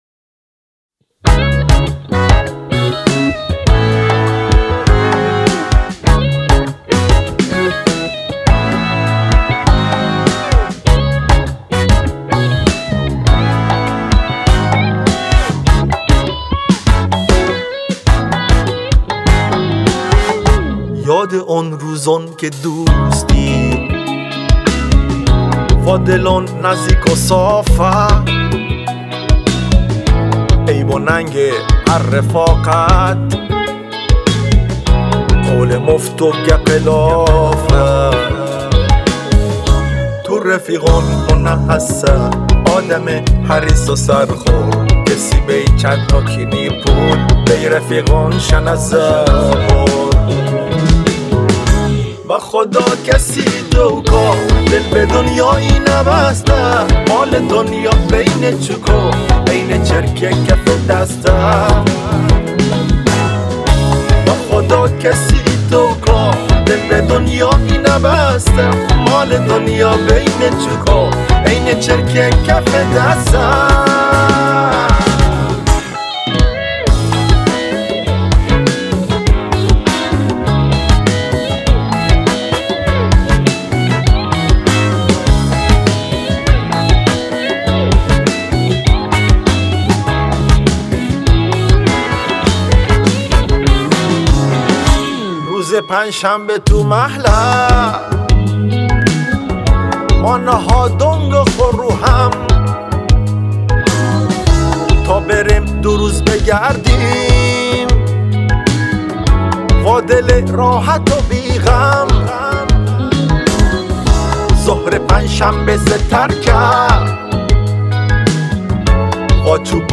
• تنظیم و گیتار باس
• نوازنده گیتار